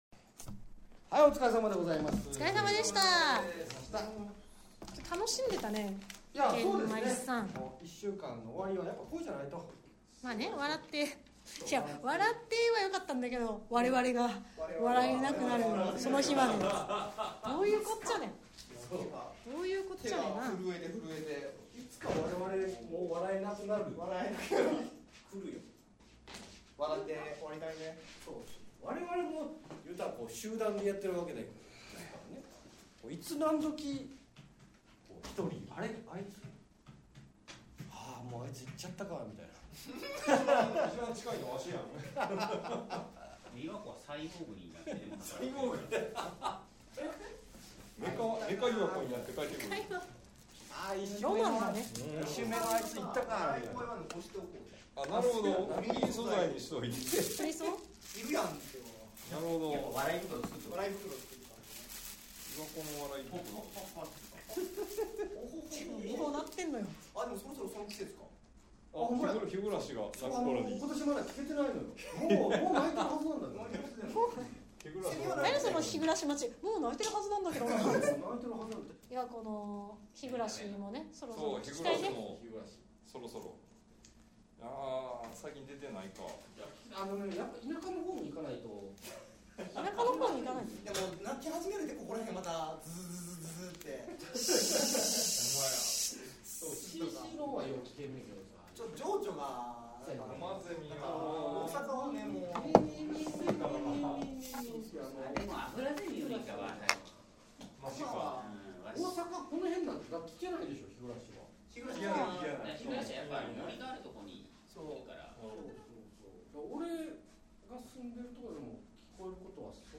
音声が出ます！音量注意です。 ■楽屋裏トーク■ https